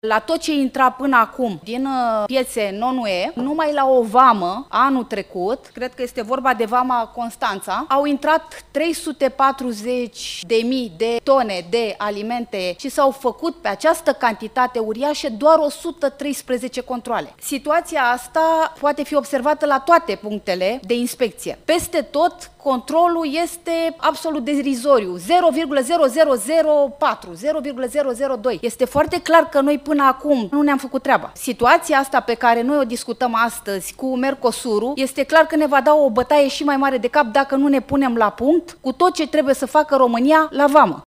La dezbaterile de astăzi din Comisia de Agricultură, deputata AUR Ionela Florența Priescu a acuzat ANSVSA că face controale artificiale în vămi.